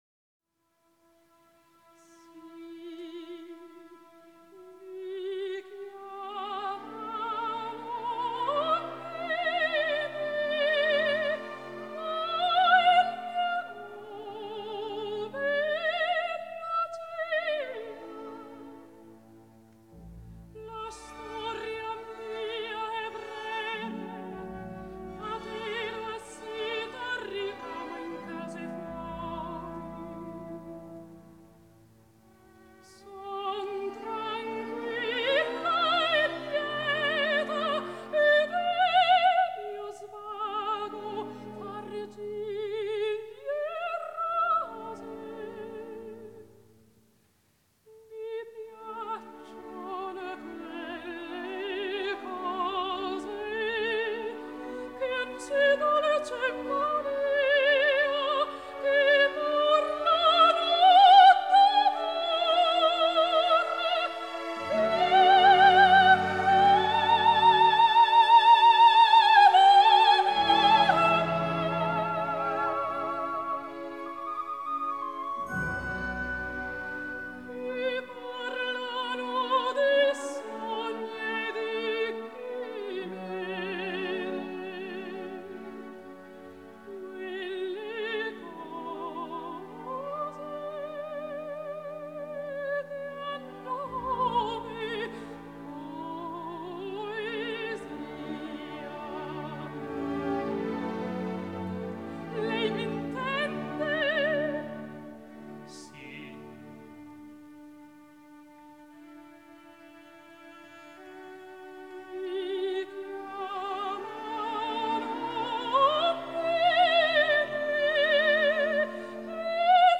лирическое сопрано